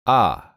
/ɒ/
Este sonido existe principalmente en inglés británico y suena como una o corta: